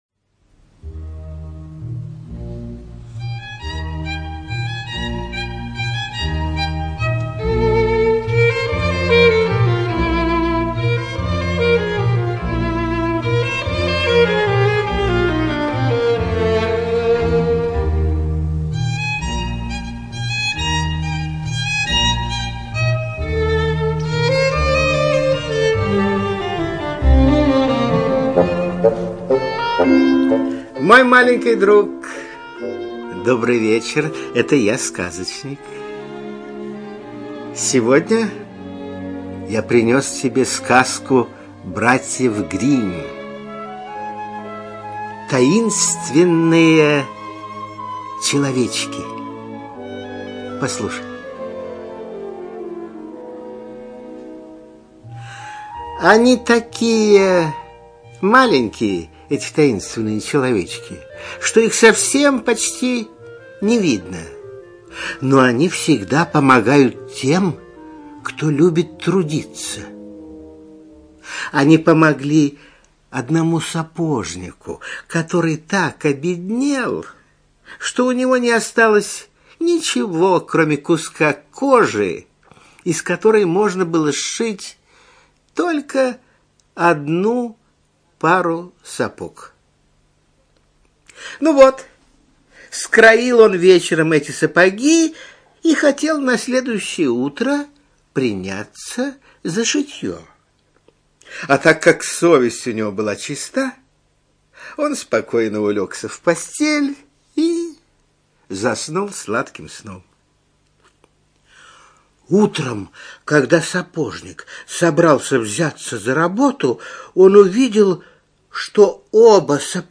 ЧитаетЛитвинов Н.